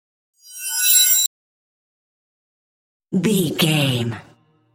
High Synth Swell.
Sound Effects
In-crescendo
Atonal
scary
ominous
eerie
synthesizer